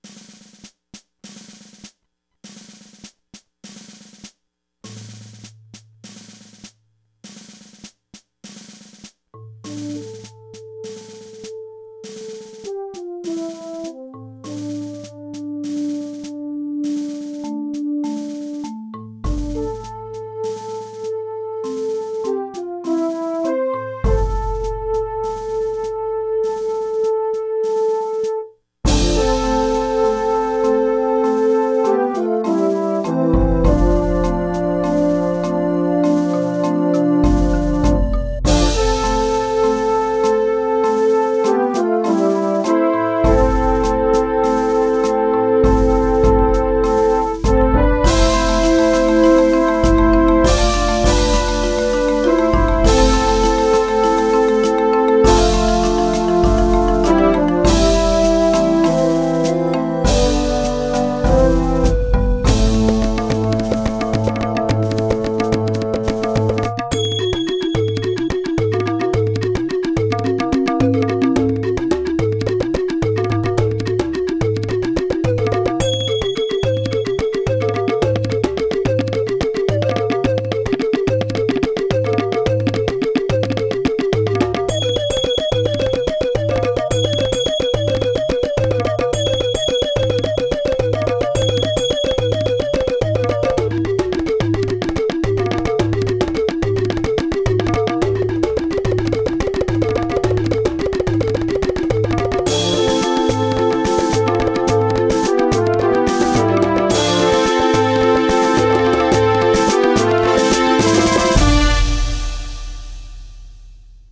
시작 팡파르